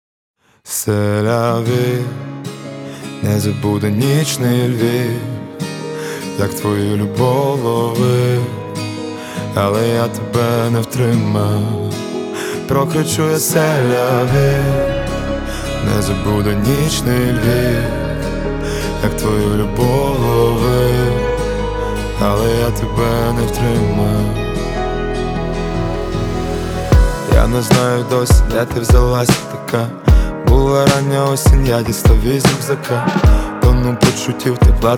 Лёгкий акцент и колоритные инструменты
Жанр: Поп музыка / Украинские